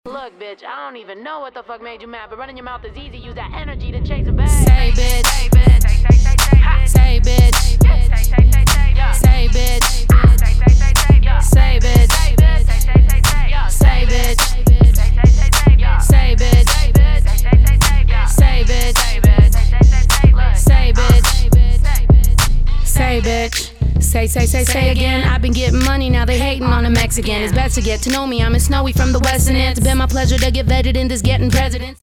• Качество: 320, Stereo
женский рэп
злые